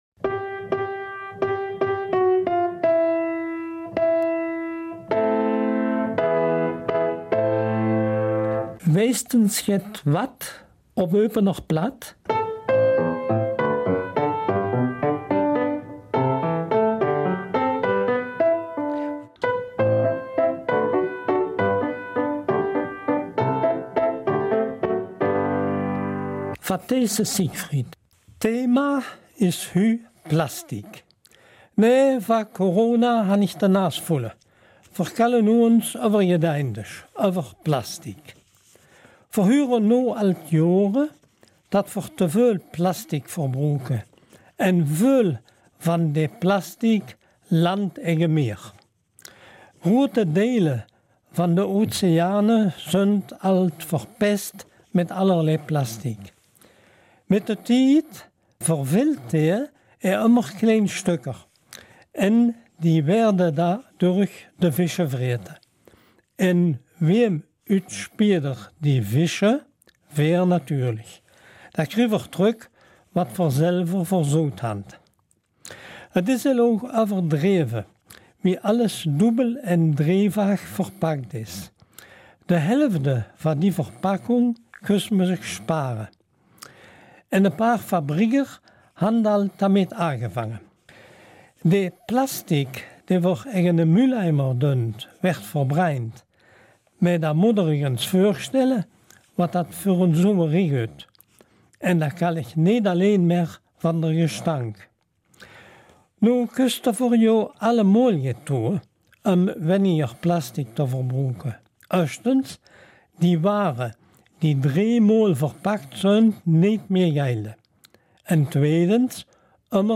Eupener Mundart: Plastik